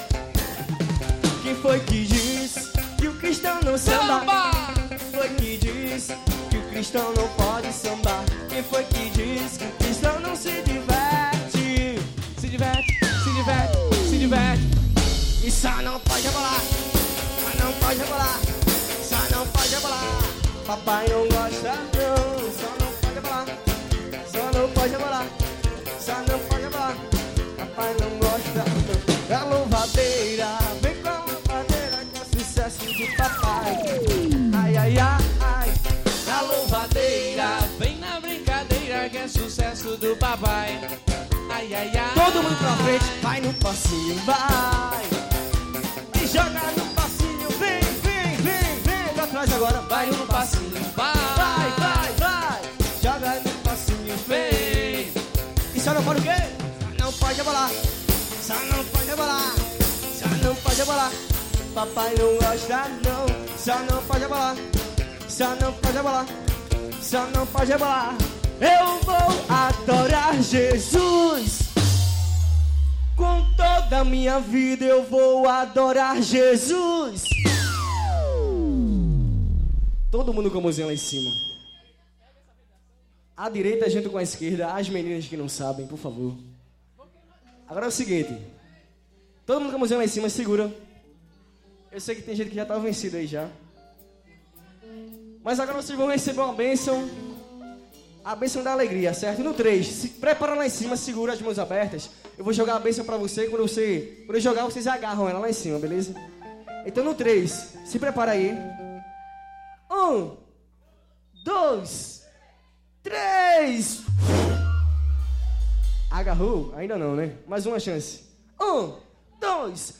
(ao vivo).